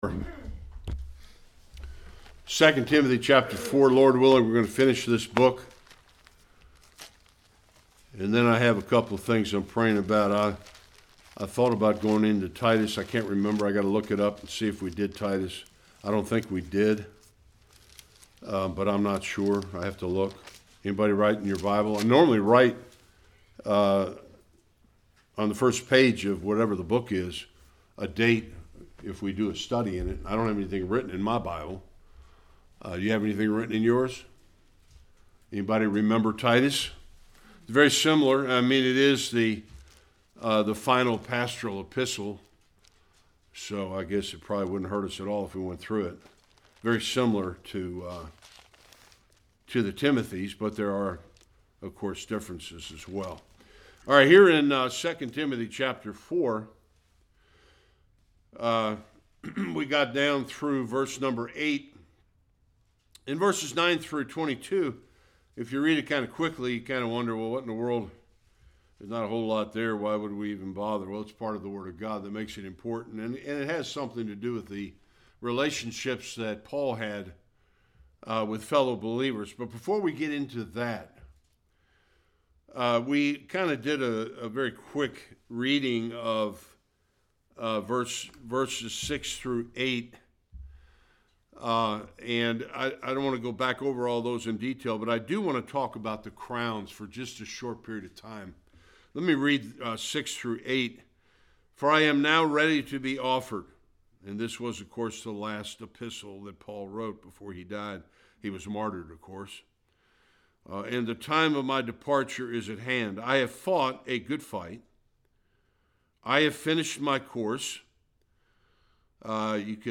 9-22 Service Type: Sunday Worship The 5 crowns available to faithful believers listed in the New Testament.